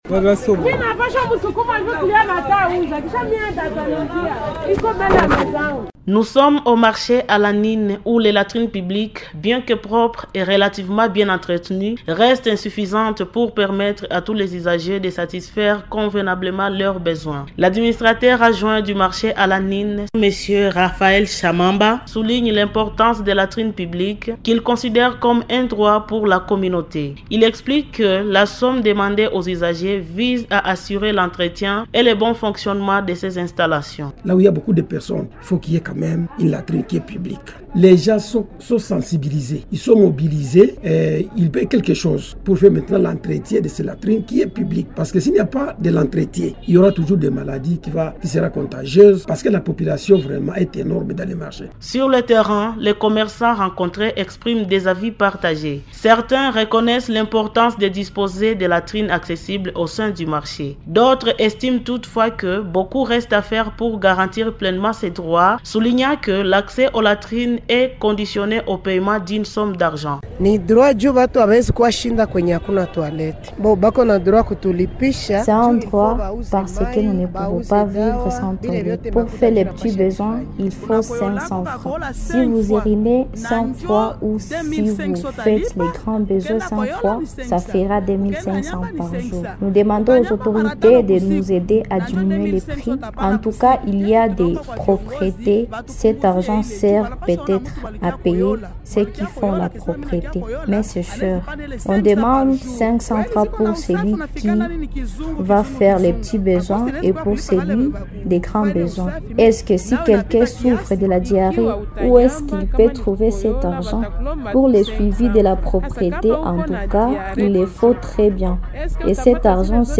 REPORTAGE-FR-LATRINES-.mp3